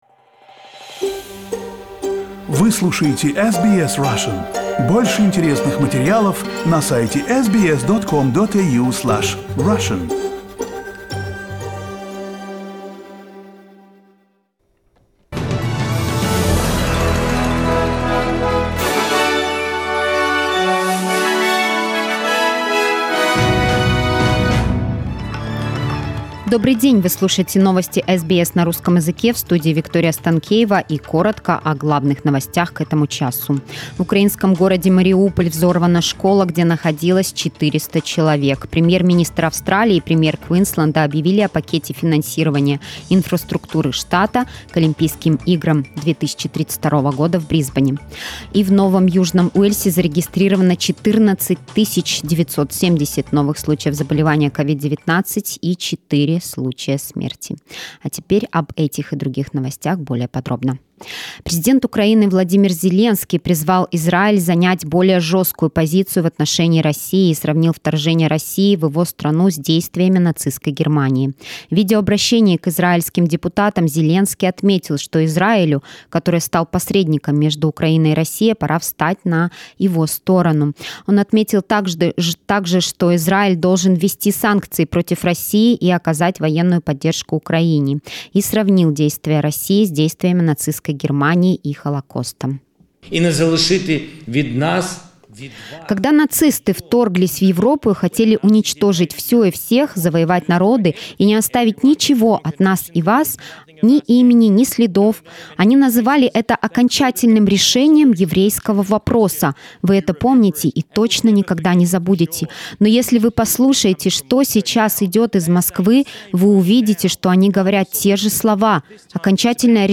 SBS news in Russian – 21.03